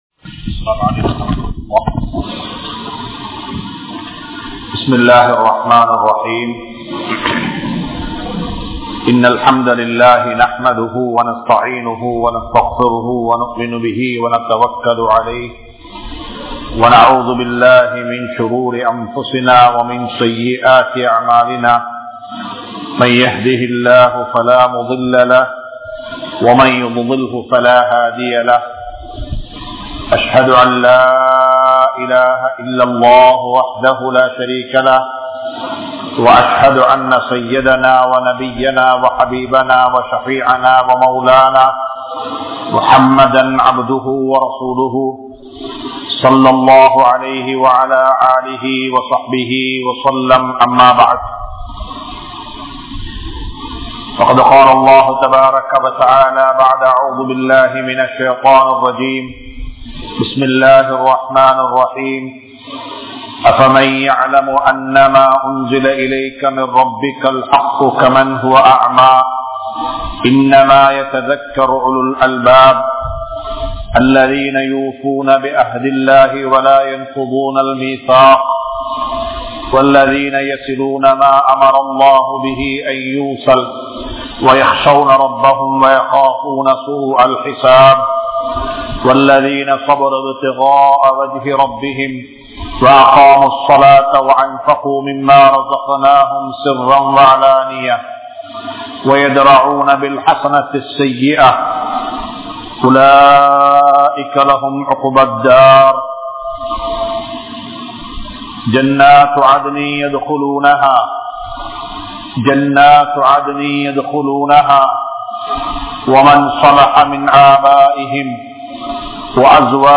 Suvarkam (Part01) (சுவர்க்கம் (பகுதி 01) | Audio Bayans | All Ceylon Muslim Youth Community | Addalaichenai
Muhideen (Markaz) Jumua Masjith